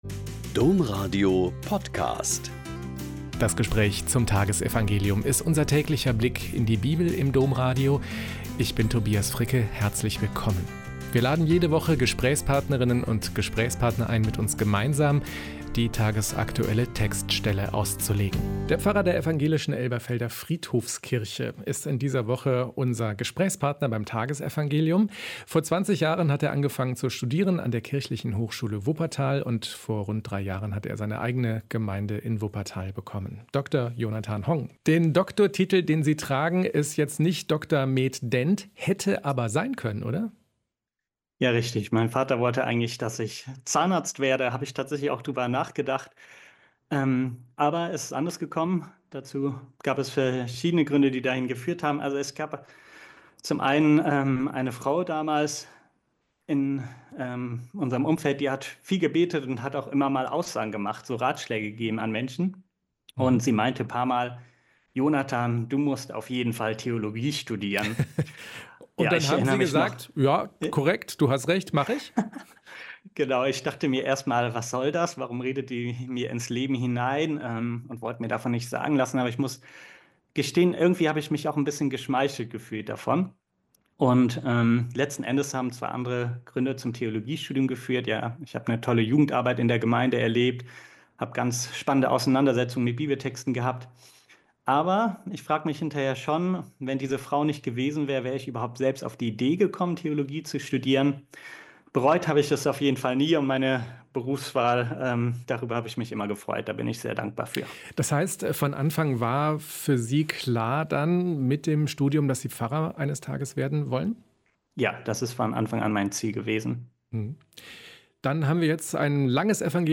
Mk 4,1-20 - Gespräch